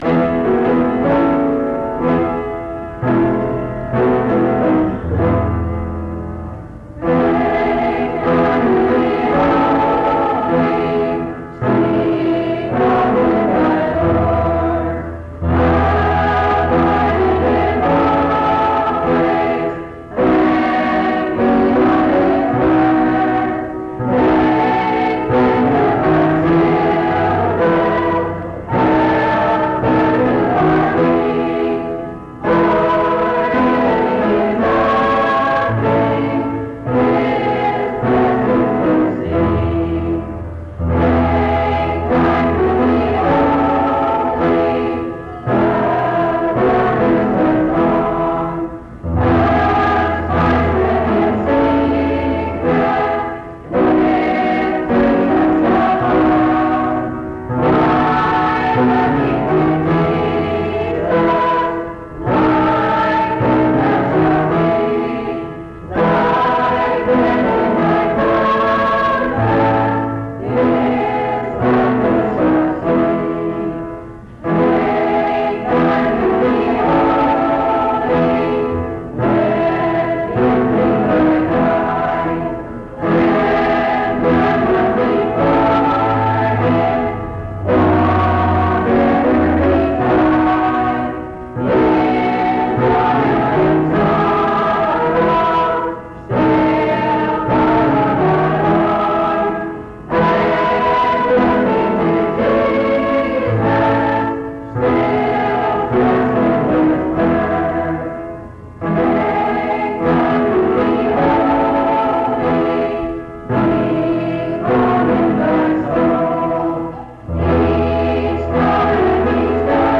Take Time to Be Holy Item 51c1a7b33b8821eddb285b6b567fbb1b91e9b4f1.mp3 Title Take Time to Be Holy Creator Mount Union II Choir Description This recording is from the Monongalia Tri-District Sing.